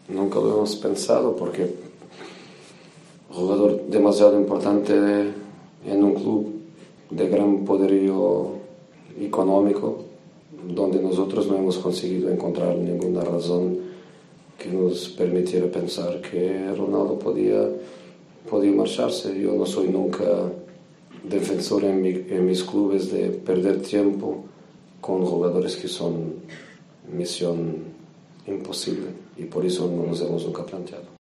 ha estado en la rueda de prensa de Mourinho. El entrenador portugués ha habado sobre Cristiano Ronaldo, reconociendo que nunca pensaron en fichar al delantero del Real Madrid.